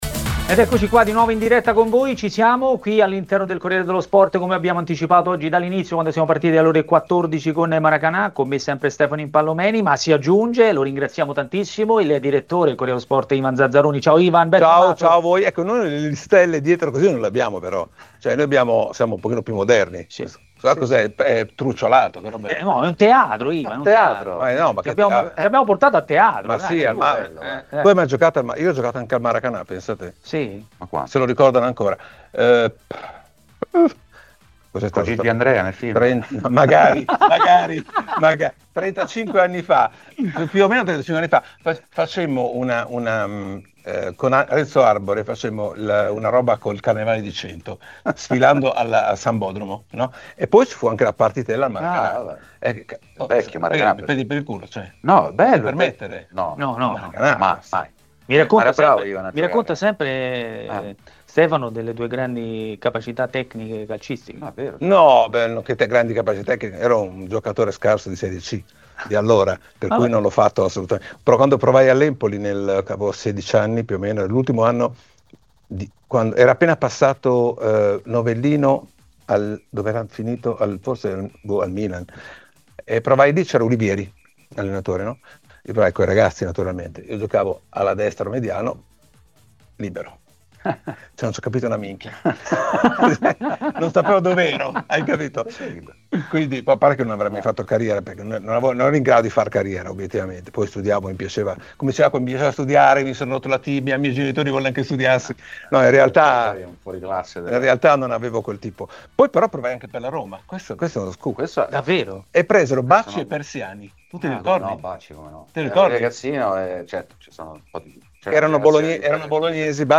A intervenire a TMW Radio, durante Maracanà, è stato il direttore del Corriere dello Sport Ivan Zazzaroni.